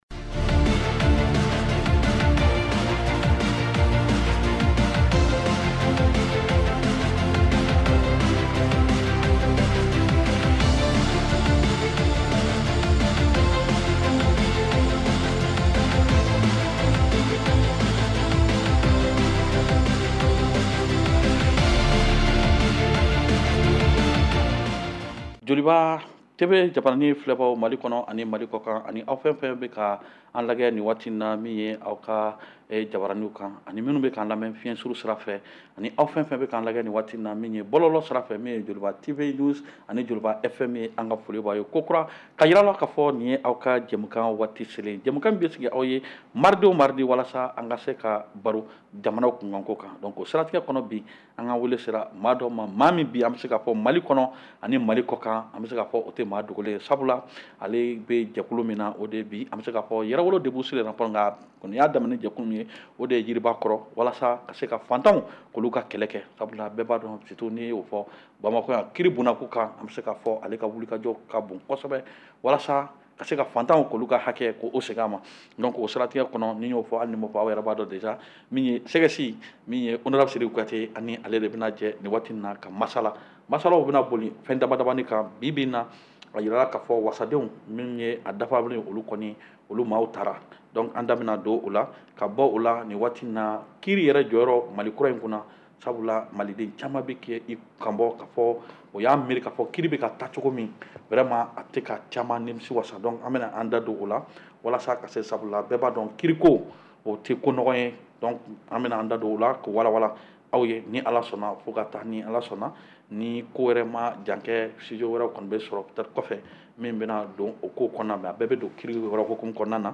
Réécoutez votre émission de débat politique en bambara.